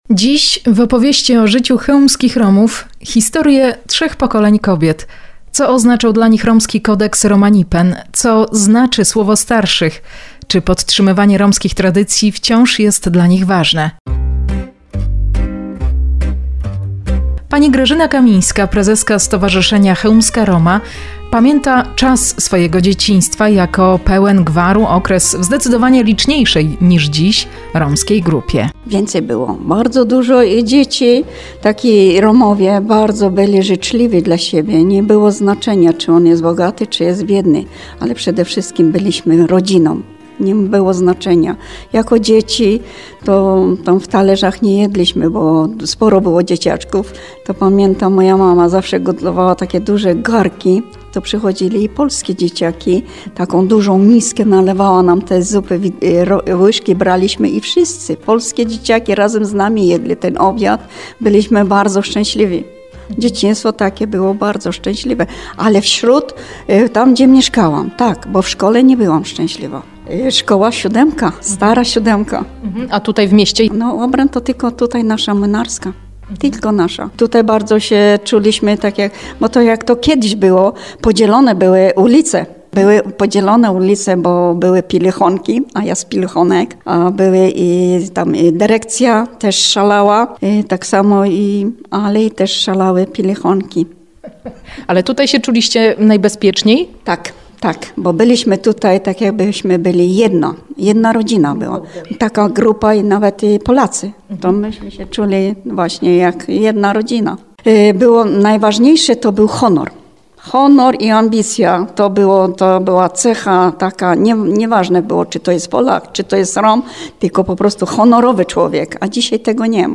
Romskie tradycje – audycja w Radio Bon Ton
Na 104.9 fm opowiadają o tym przedstawicielki trzech romskich pokoleń.